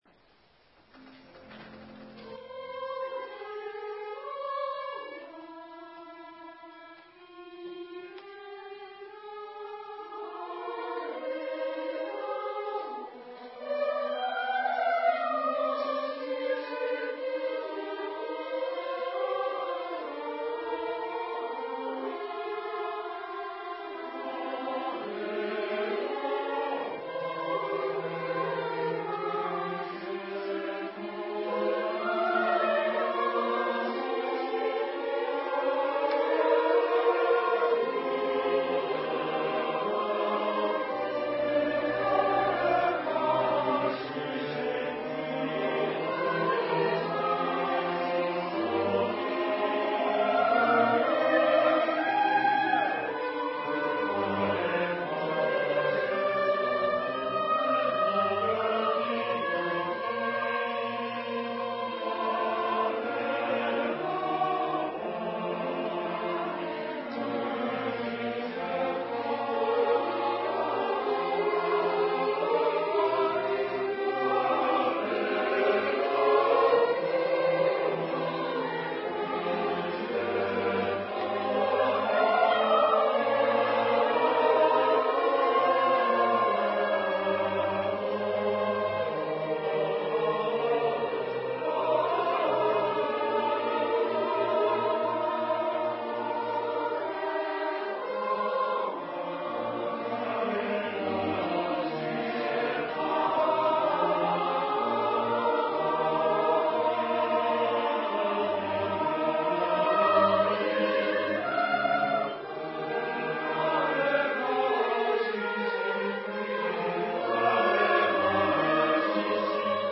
吉祥寺2002年公演録音